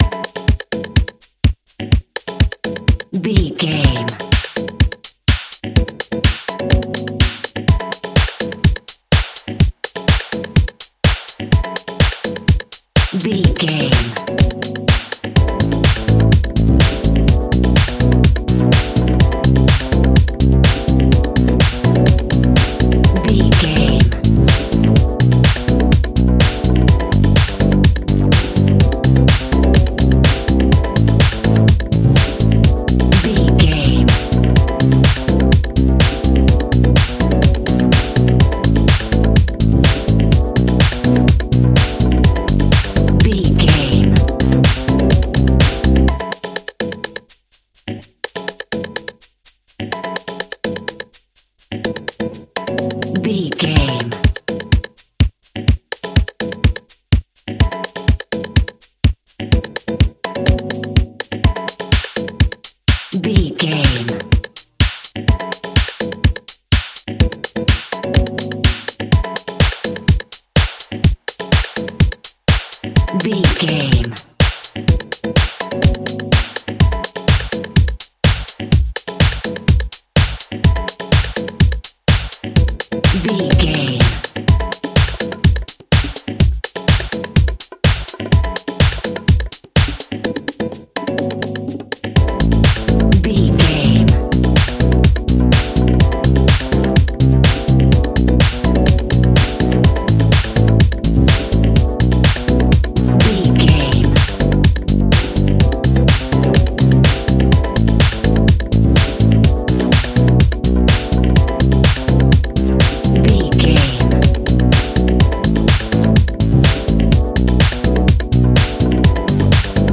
Aeolian/Minor
groovy
funky house
chart dance
electronic
deep house
upbeat
driving
electronic drums
synth lead
synth bass